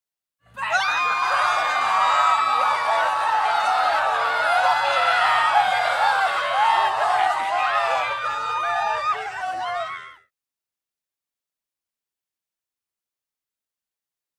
دانلود آهنگ ترس و وحشت و فرار مردم از افکت صوتی انسان و موجودات زنده
دانلود صدای ترس و وحشت و فرار مردم از ساعد نیوز با لینک مستقیم و کیفیت بالا
جلوه های صوتی